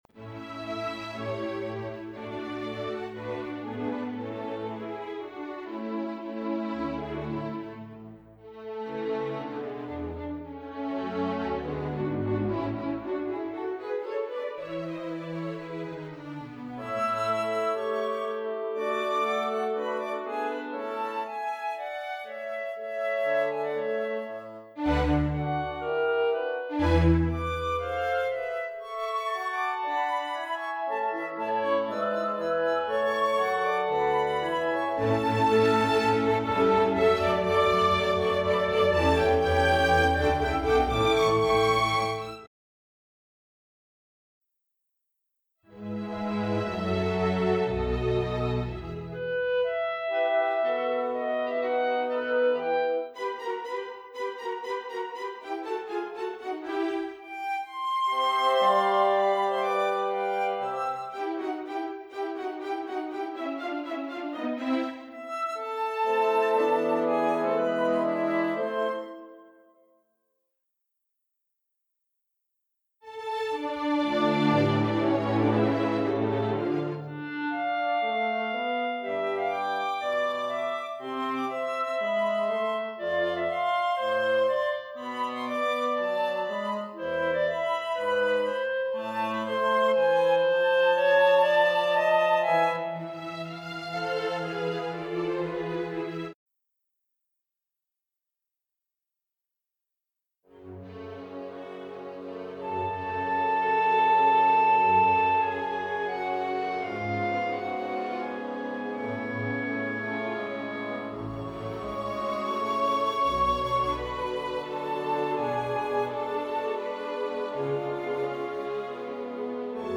Si tratta di basi orchestrali, cameristiche e pianistiche.
BASI ORCHESTRALI